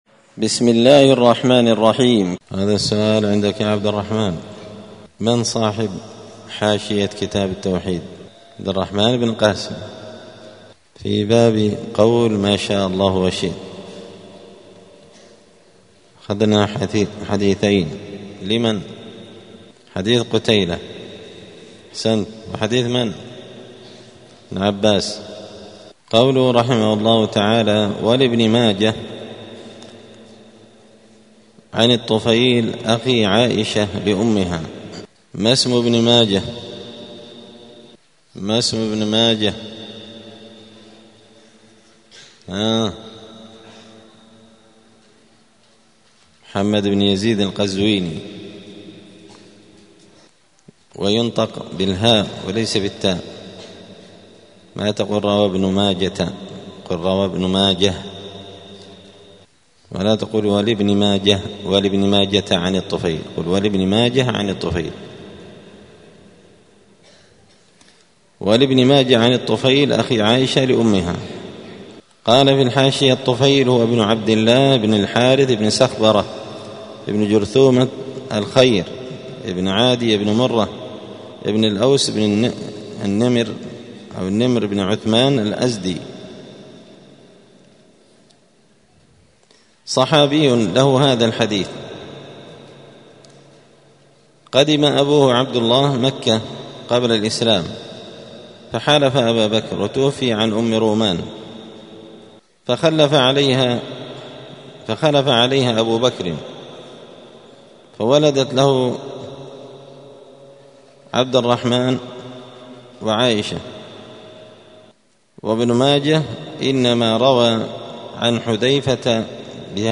دار الحديث السلفية بمسجد الفرقان قشن المهرة اليمن
*الدرس الثالث والعشرون بعد المائة (123) {تابع لباب قول ماشاء الله وشئت}*